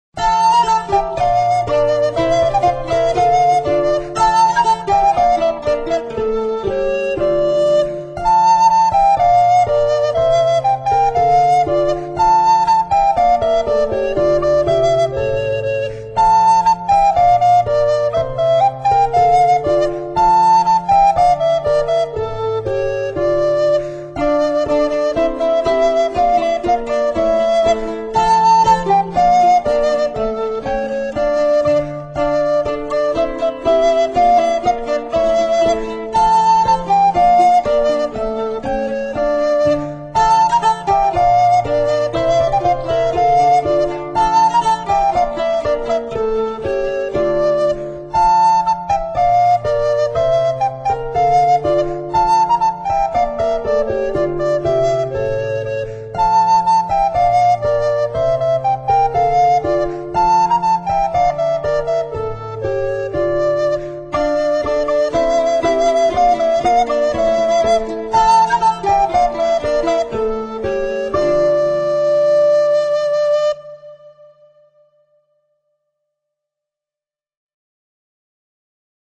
clavicembalo
flauti a becco e traversi, violino, mandola
organo, arpa, percussioni.